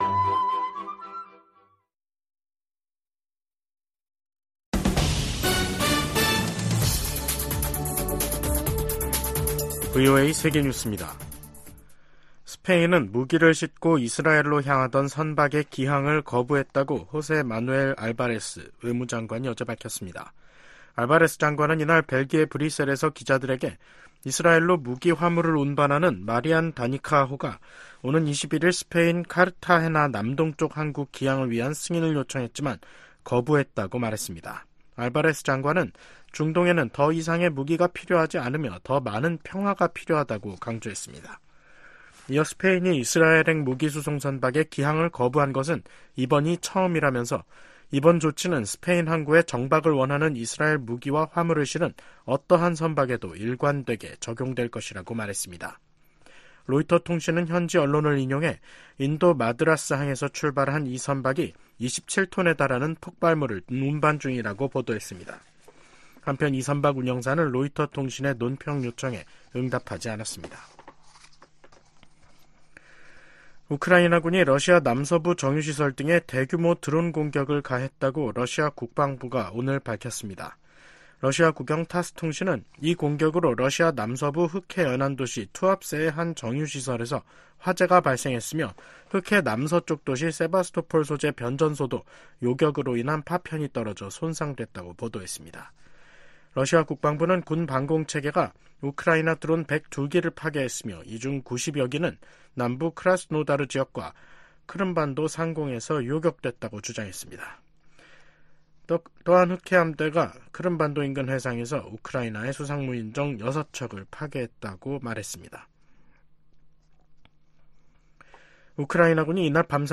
VOA 한국어 간판 뉴스 프로그램 '뉴스 투데이', 2024년 5월 17일 2부 방송입니다. 북한이 오늘 동해상으로 단거리 미사일 여러 발을 발사했습니다.